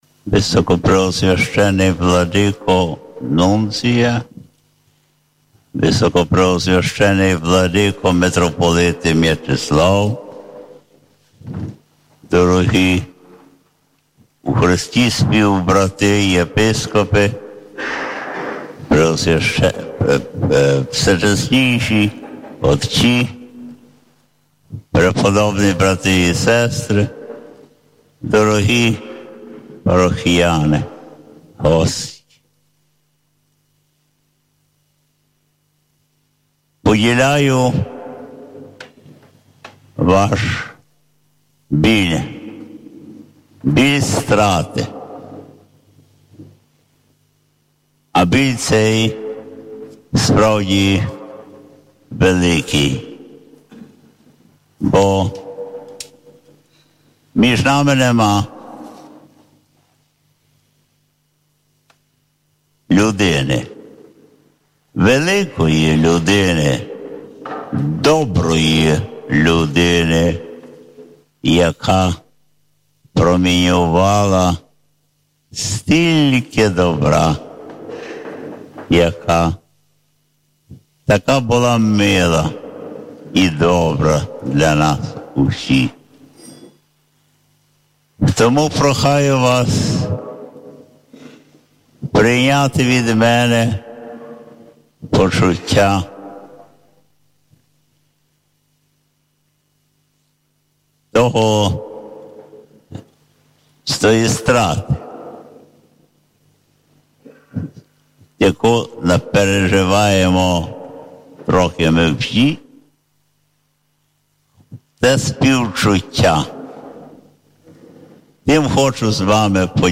У соборі св. Олександра у Києві відбулася прощальна Свята Меса з архиєпископом Петром Мальчуком – ординарієм Києво-Житомирської дієцезії.
Звернувся зі словом до вірних Блаженніший Любомир (Гузар): «Коли сьогодні ми входили до храму, нам було сумно, в очах багатьох людей були сльози, але це цілком природно… Поділяю ваш біль втрати, адже між нами немає великої людини, яка випромінювала стільки добра, була милою та доброю для нас усіх!
Гузар-на-похороні.mp3